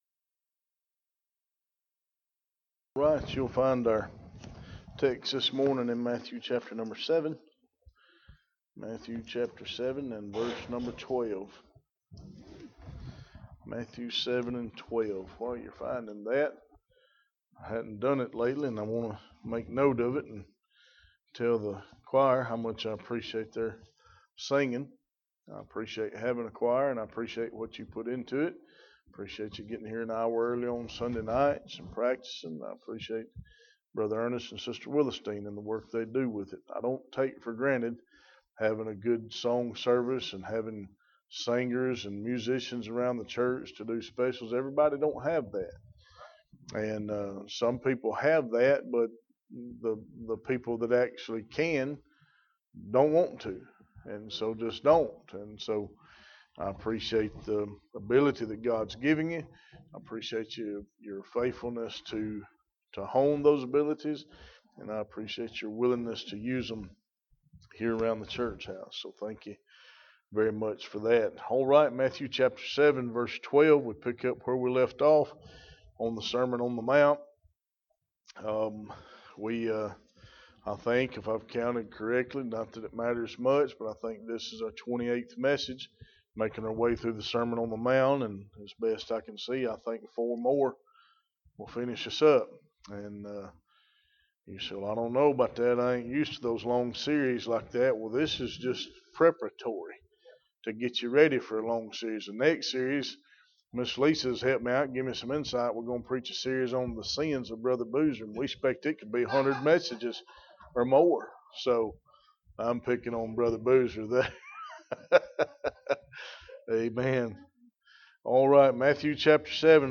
Sermon on the Mount Passage: Matthew 7:12 Service Type: Sunday Morning « When you Can’t Find God